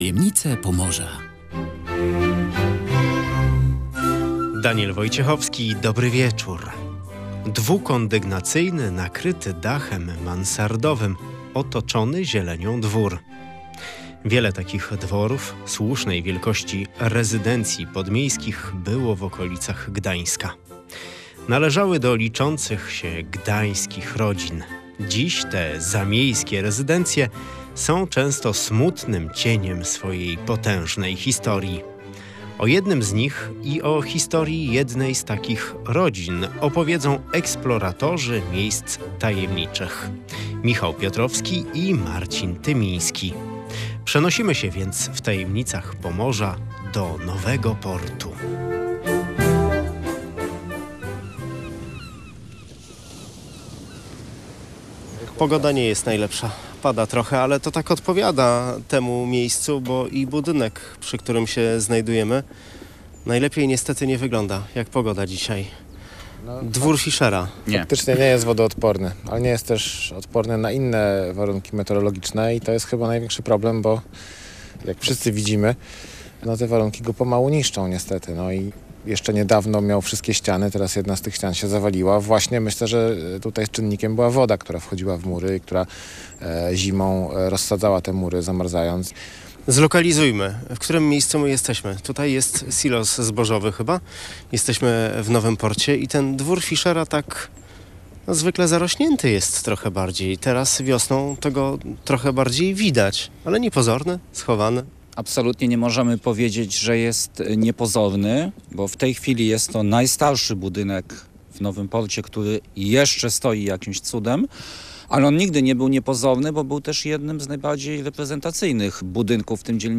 O Dworze Fischera w Nowym Porcie opowiedzieli eksploratorzy miejsc tajemniczych